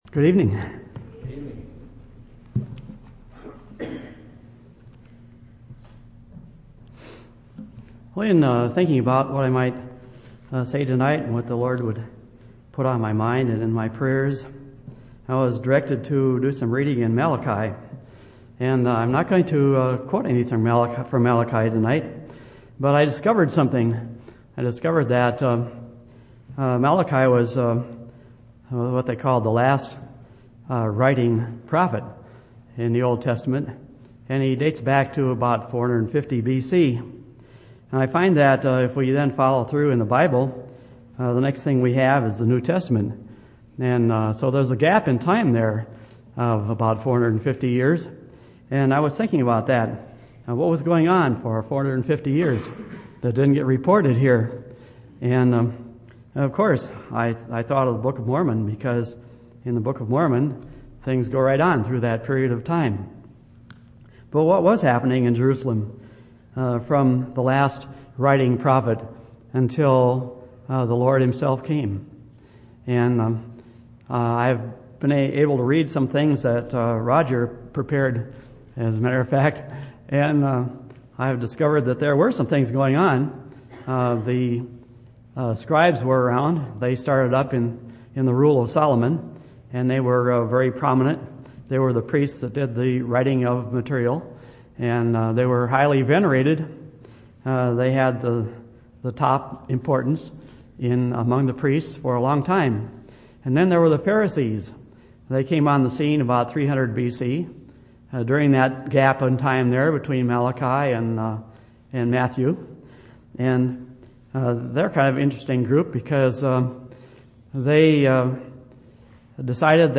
9/1/2002 Location: Temple Lot Local Event